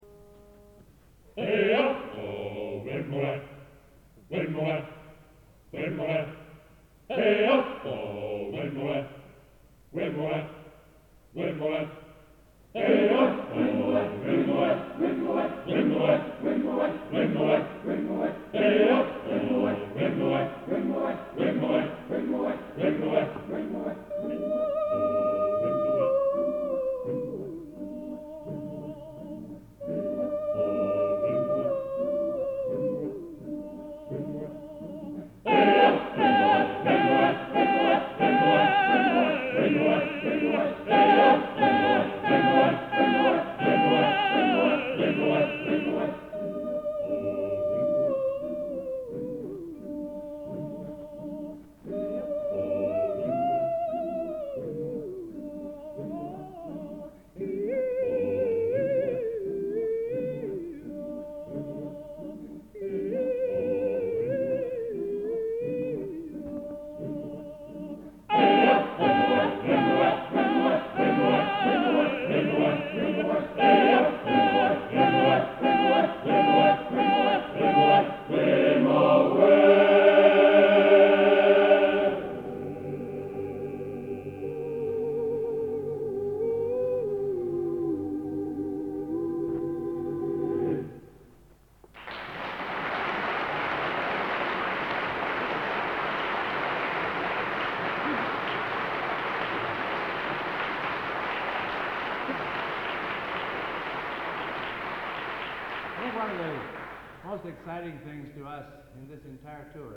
Location: Plymouth, England